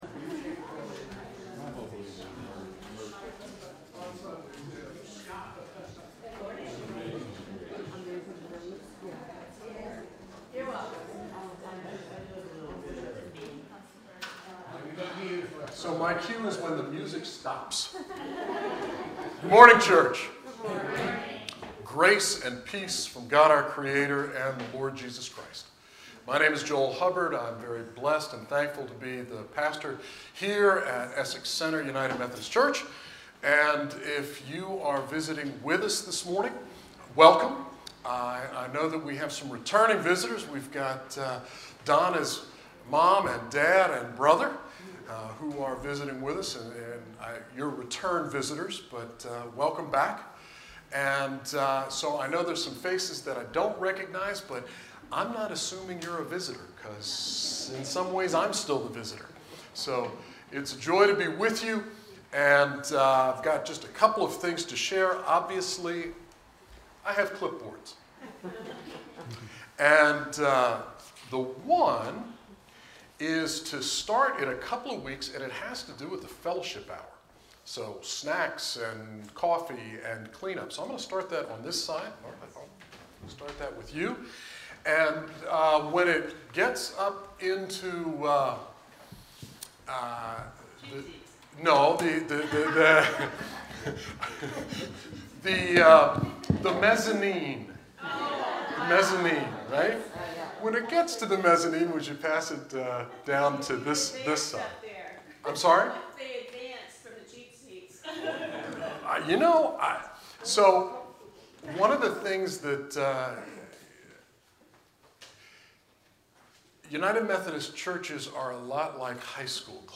You are welcome to join us for in-person worship in the Essex Center UMC sanctuary, you may participate on Facebook Live by clicking here,Read more
Sermons